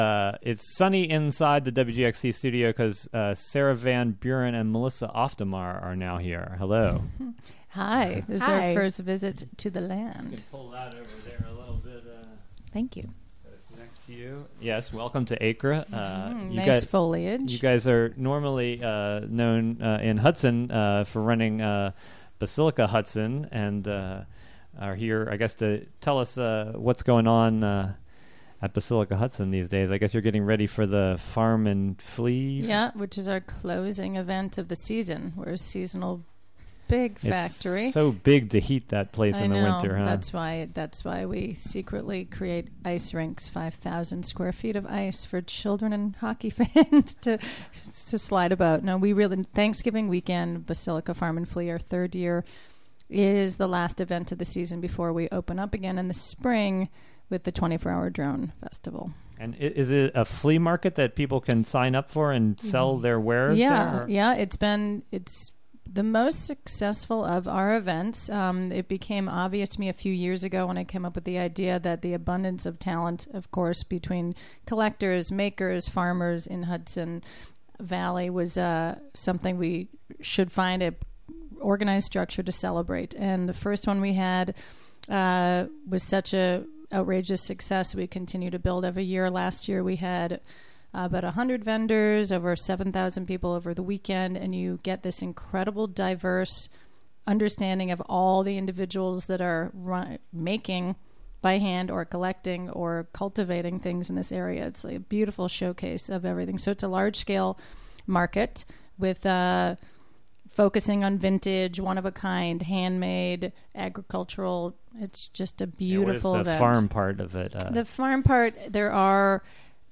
The WGXC Morning Show is a radio magazine show fea...